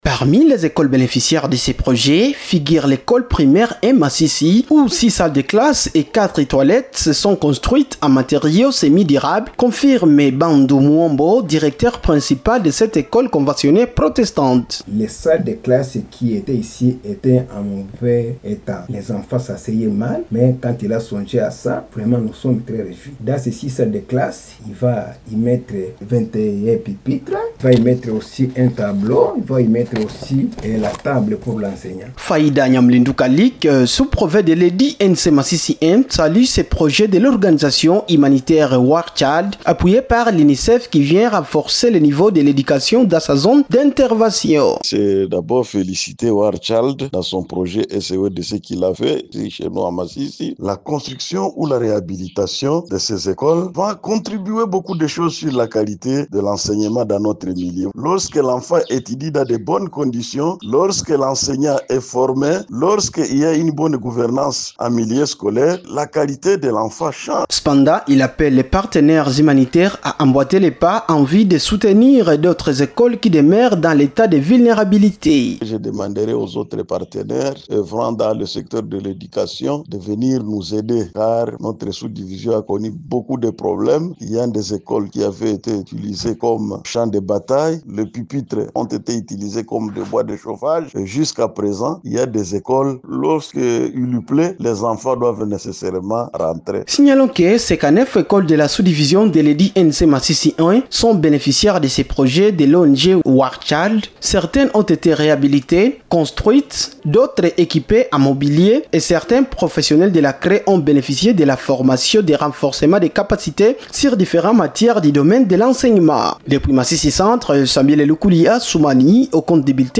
REPOERTAGE-CONSTRUCTION-ECOLES-FR.mp3